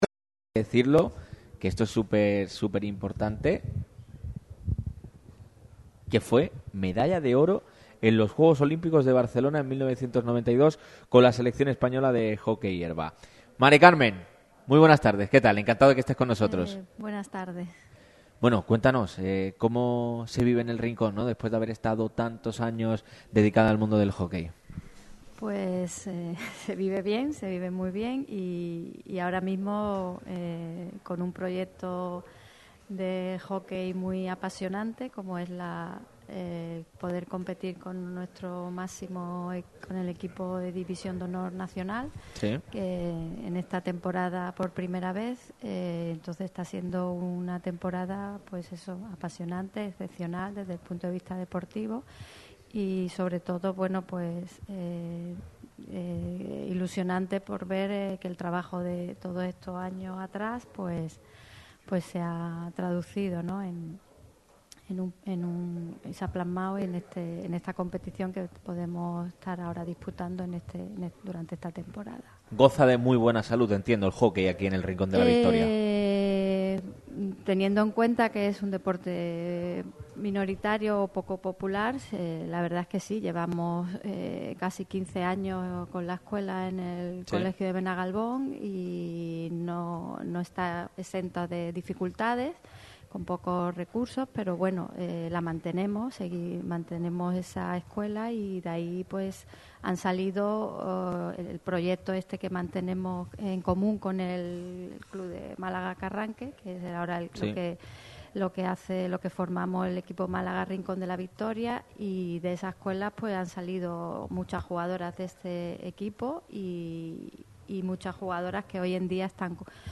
La emisora líder de la radio deportiva malagueña ha llevado a cabo este viernes 24 de octubre un nuevo programa especial en un lugar con mucha historia. Radio MARCA Málaga se ha desplazado al museo arqueológico de Villa Antiopa en Torre de Benagalbón (Rincón de la Victoria).